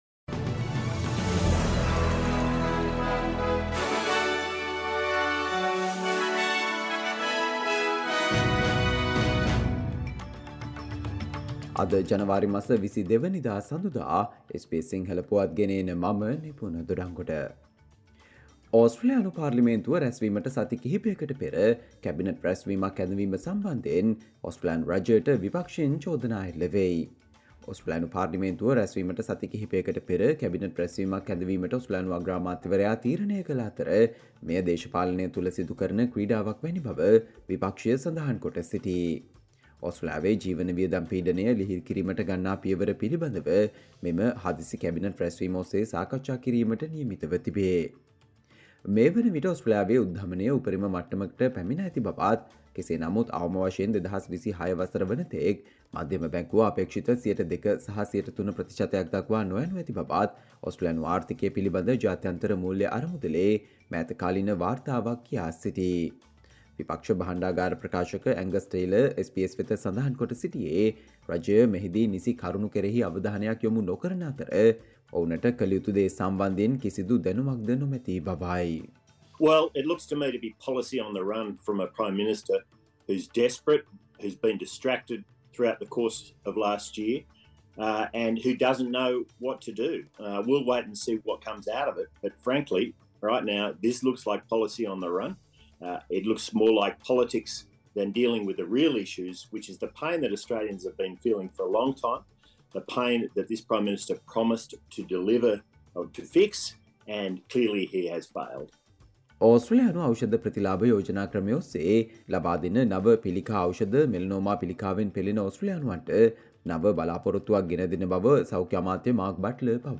Australia news in Sinhala, foreign and sports news in brief - listen, Monday 22 January 2024 SBS Sinhala Radio News Flash